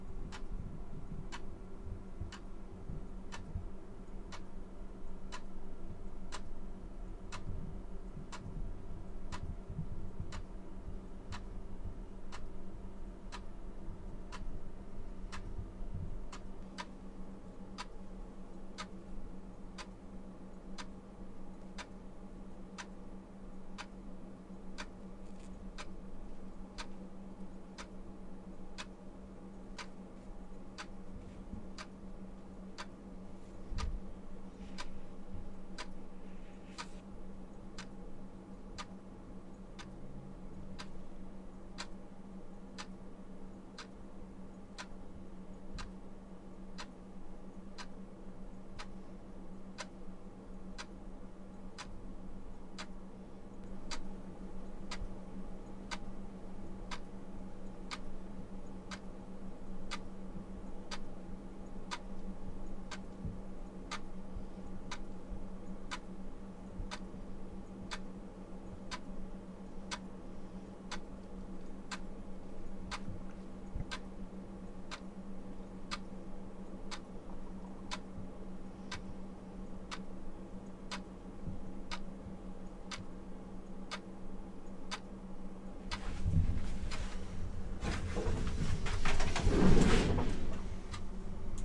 钟声响起
描述：时钟在中等大小的房间里滴答作响。只是基本的氛围
Tag: 挂钟 滴答滴答 抽动症 环境 时间 滴答作响的时钟 钟表